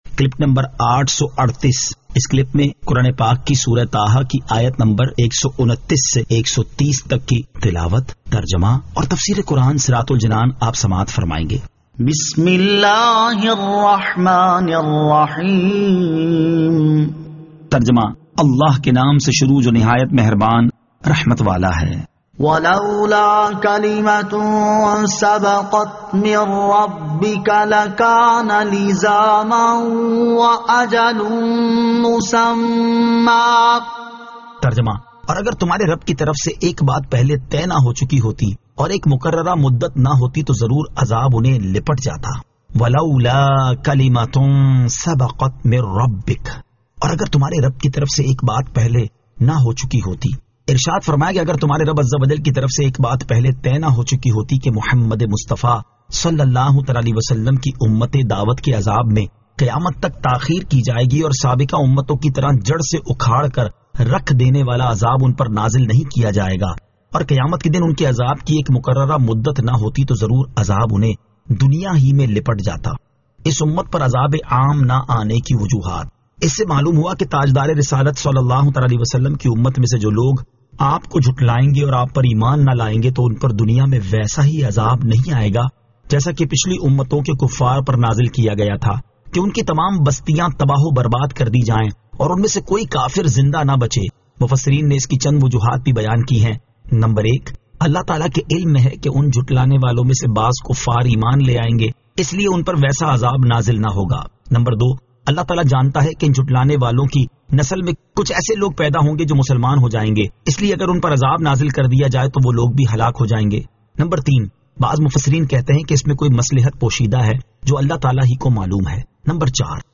Surah Taha Ayat 129 To 130 Tilawat , Tarjama , Tafseer